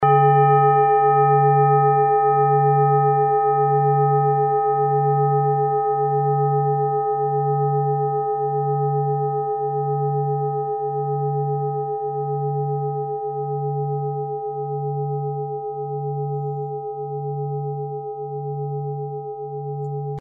Diese Klangschale ist eine Handarbeit aus Bengalen. Sie ist neu und wurde gezielt nach altem 7-Metalle-Rezept in Handarbeit gezogen und gehämmert.
Klangschale Bengalen Nr.18
Hörprobe der Klangschale
Filzklöppel
Der Marston liegt bei 144,72 Hz, das ist nahe beim "D".